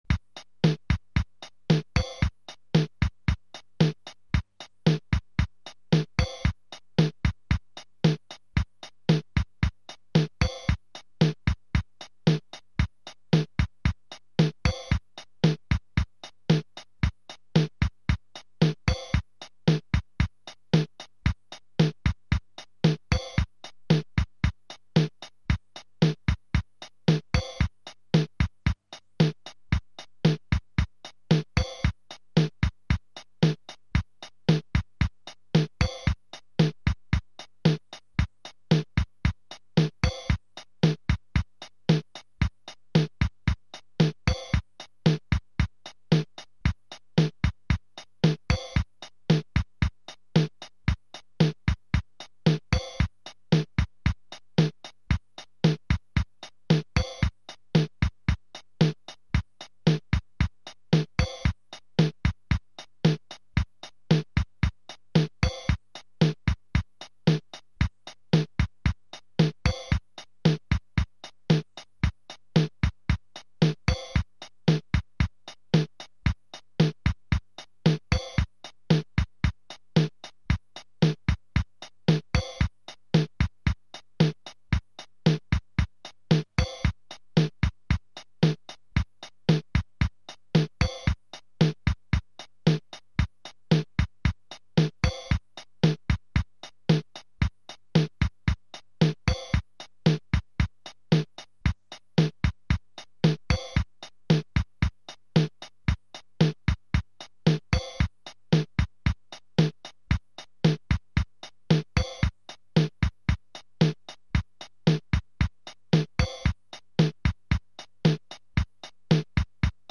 Turn on your speakers and listen to the beat in 4/4 time.
metronome.mp3